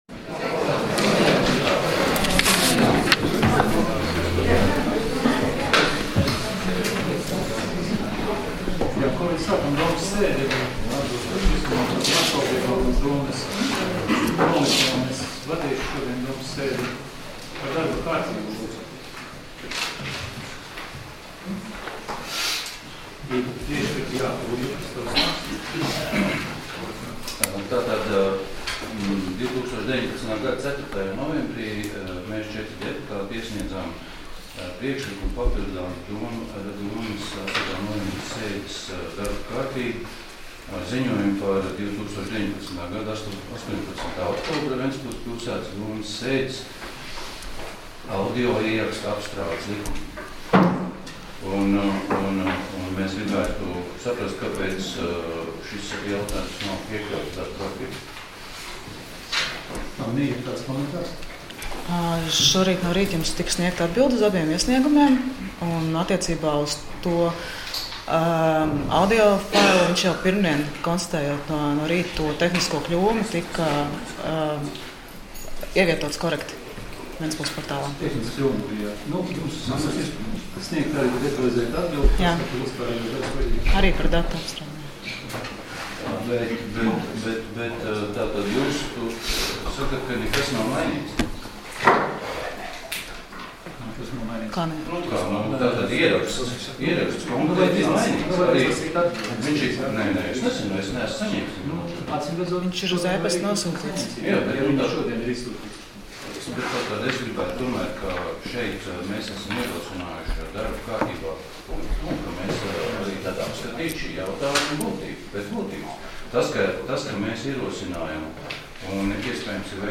Domes sēdes 08.11.2019. audioieraksts